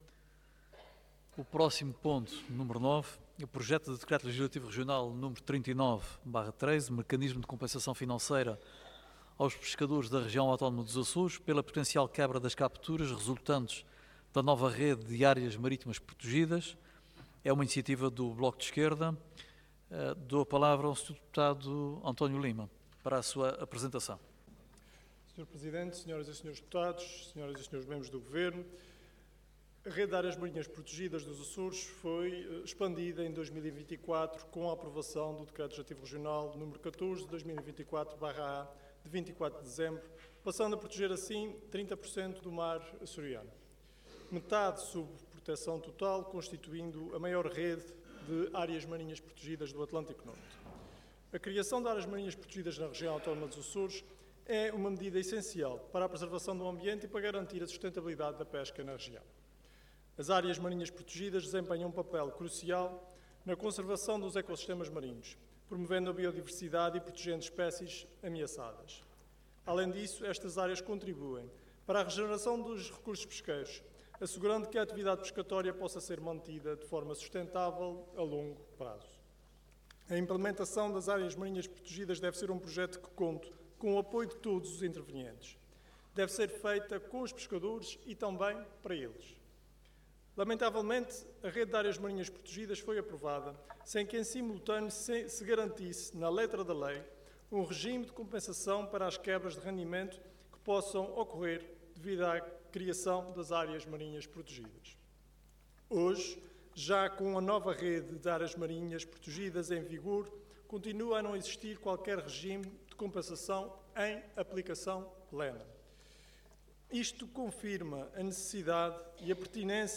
Intervenção
Orador António Lima Cargo Deputado Entidade BE